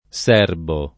serbare v.; serbo [
S$rbo] — antiq. l’allòtropo dòtto servare, che aveva sign. in parte diversi (spec.